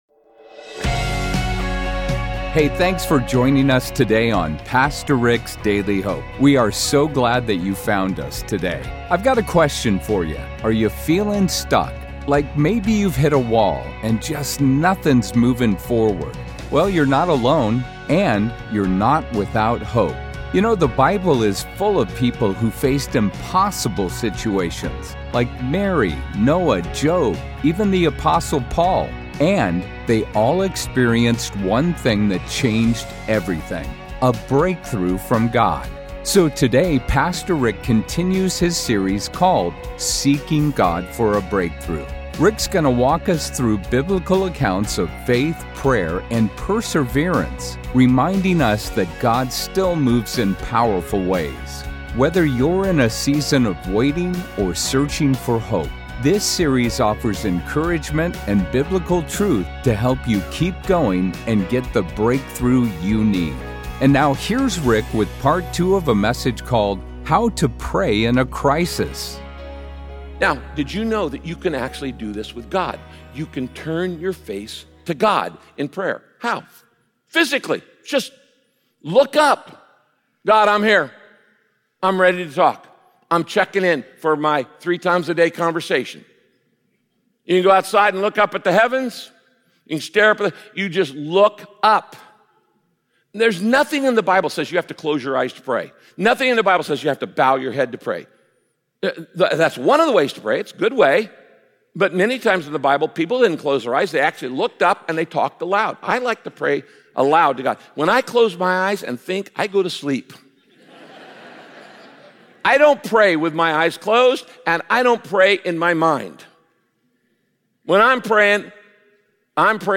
There are many ways God uses adversity for your good. Listen to this message by Pastor Rick, and learn how to trust in God's goodness even when you don't unders…